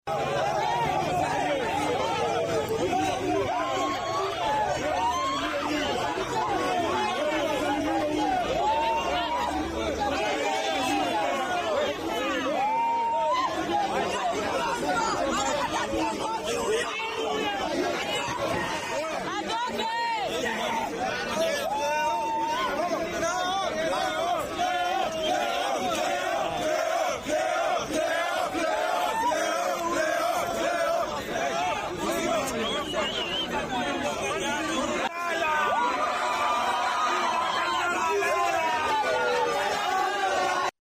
Jawiga xarunta dhexe ee UDA ayaa maanta sidatan ahayd ka hor inta aynan ciidamada ammaanka xaaladda dejin.